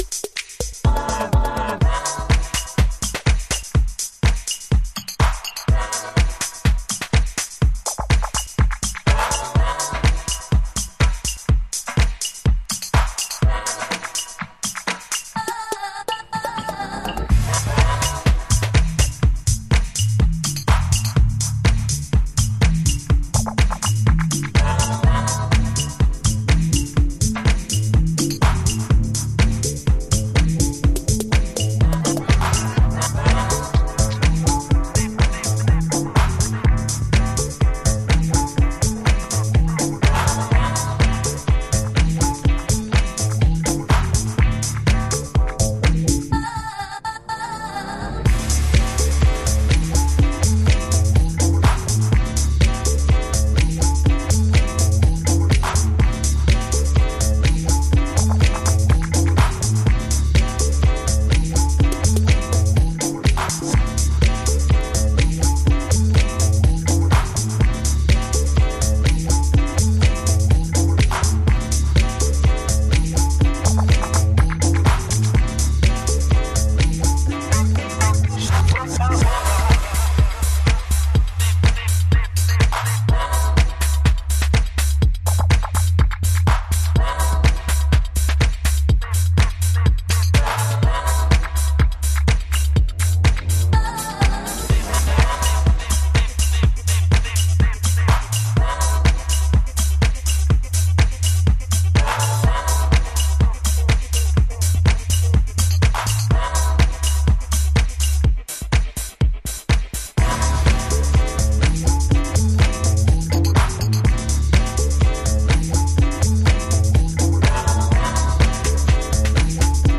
煌びやかなパーティー・チューンをメイク。
Alt Disco / Boogie